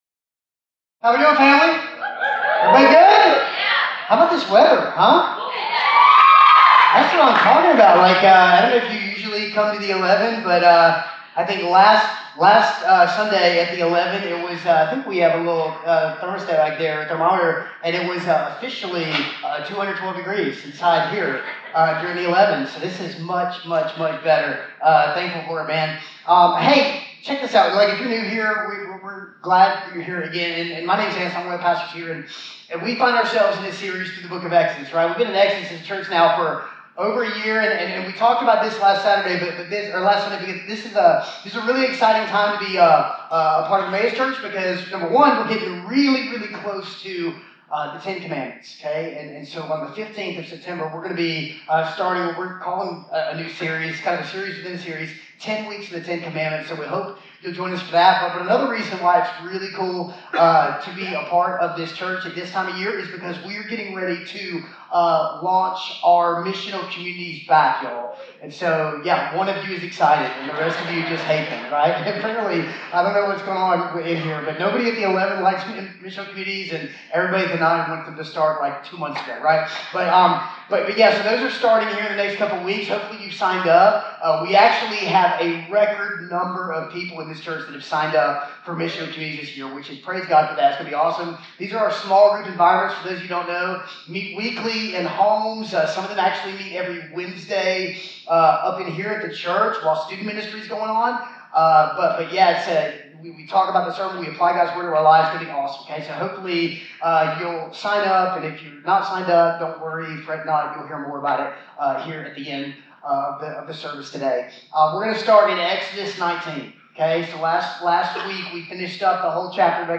***WE APOLOGIZE FOR THE SOUND QUALITY OF THIS PODCAST***